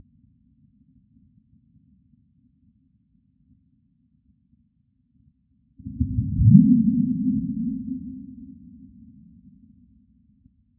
The damaging M7.8 in Nepal, including some aftershocks, came rumbing through over 3 hours.
Listen to the Nepal quake as a WAV file, filtered 0.01 Hz to 0.07 Hz and sped up many times.  One second of audio time is just over 1 hour of real time.
M7.9_nepal.wav